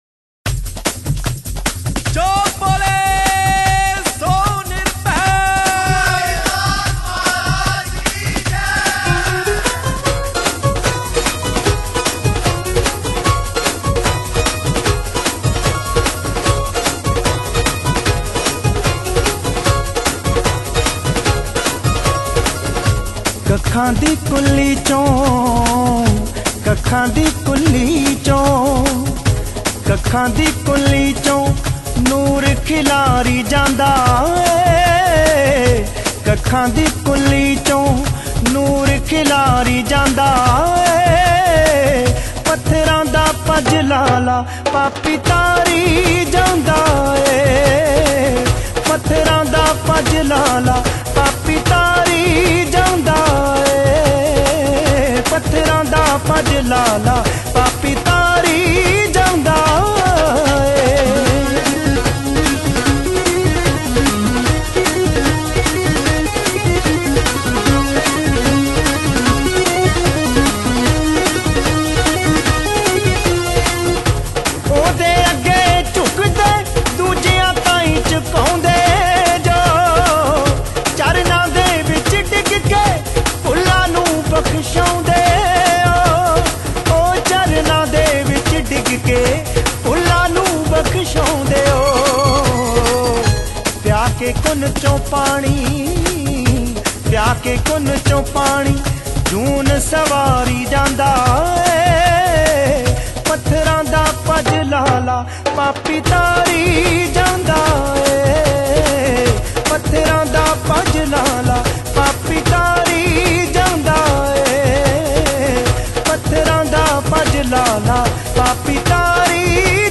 Category: Bhakti Sangeet